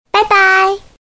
男人甜美说拜拜音效_人物音效音效配乐_免费素材下载_提案神器